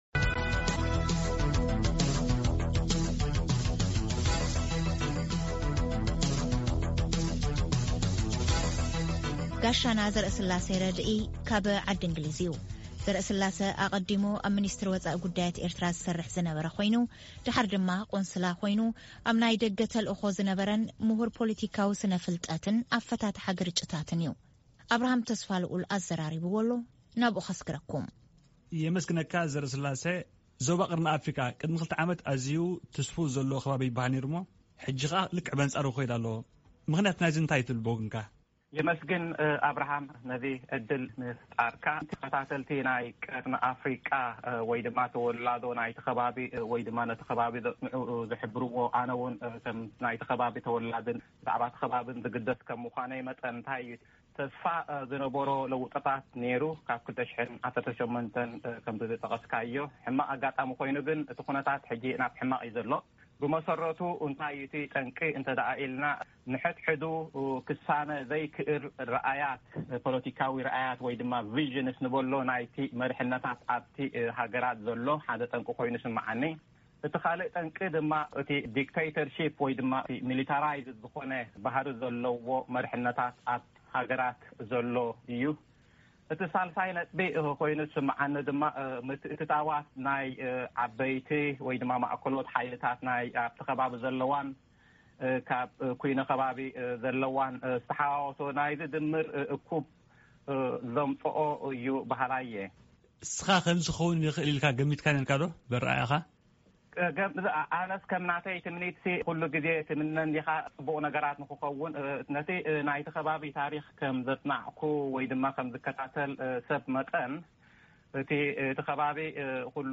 ቃለ ምልልስ ኣብ ጉዳይ ኣብ ቀርኒ ኣፍሪቃ ዝረአ ዘሎ ምዕባለታት